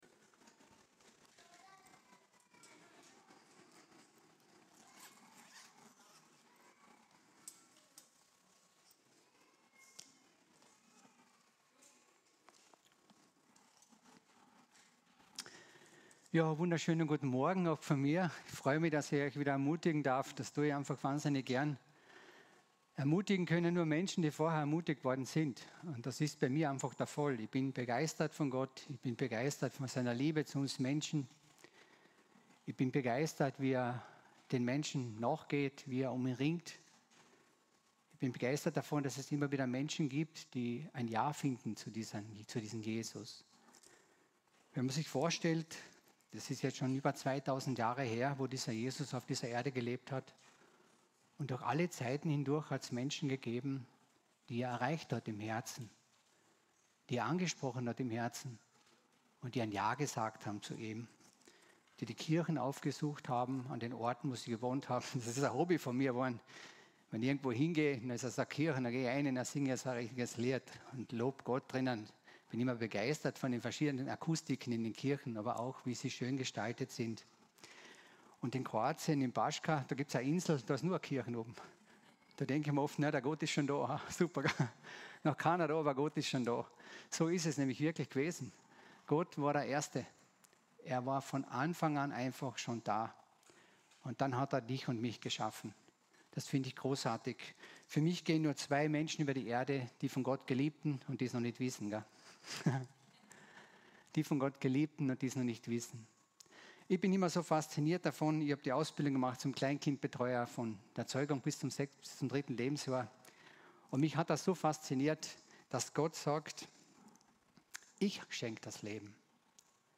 Eine predigt aus der serie "Einzelpredigten 2024."